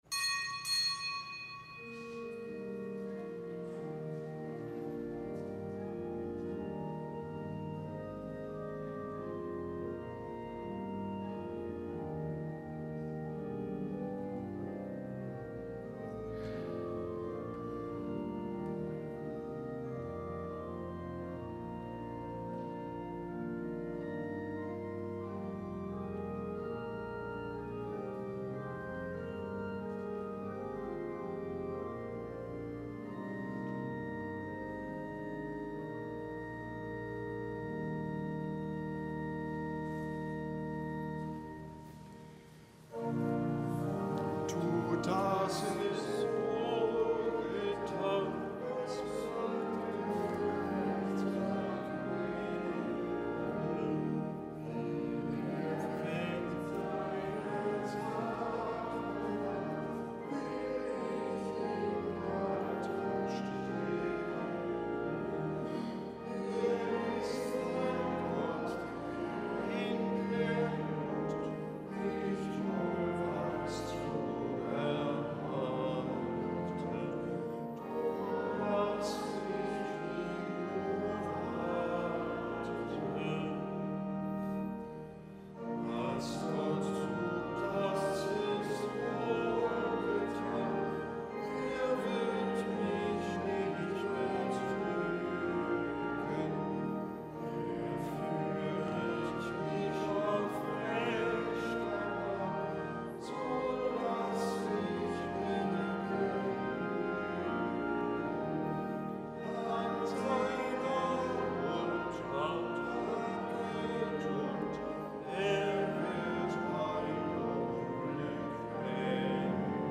Kapitelsmesse am Dienstag der einundzwanzigsten Woche im Jahreskreis
Kapitelsmesse aus dem Kölner Dom am Dienstag der einundzwanzigsten Woche im Jahreskreis.